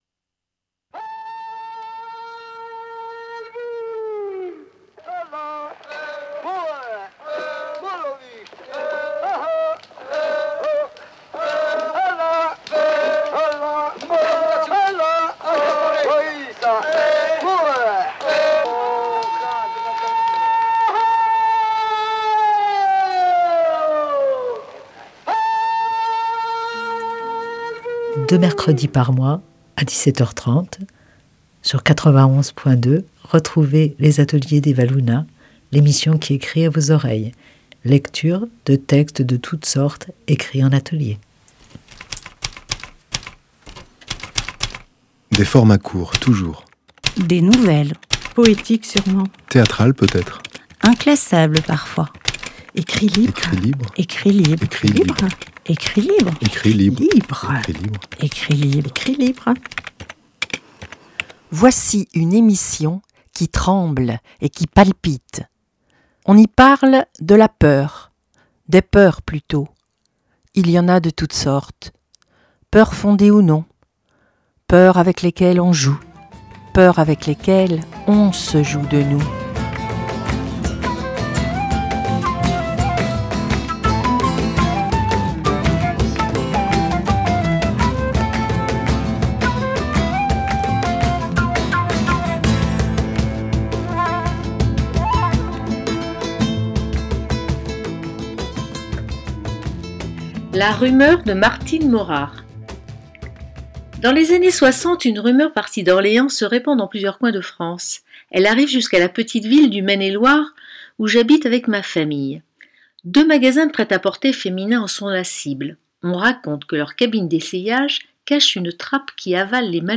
En trame musicale